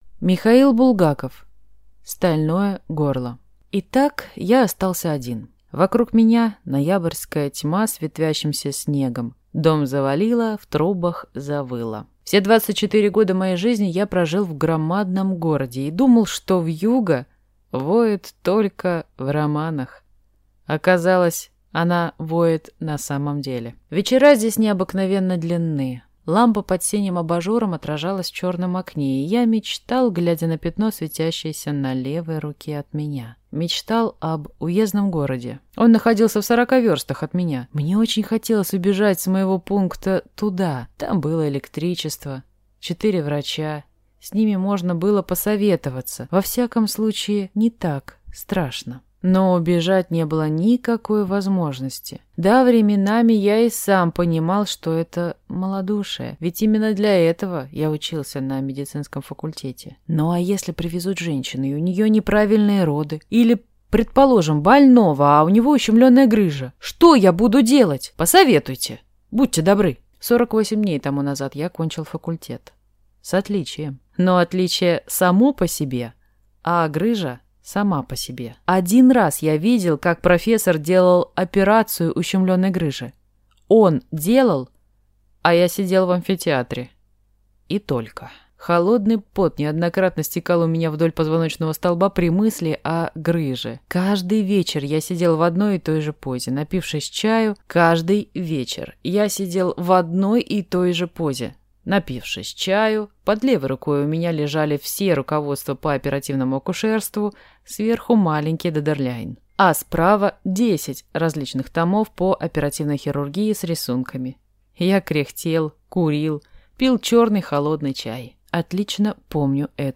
Аудиокнига Стальное горло | Библиотека аудиокниг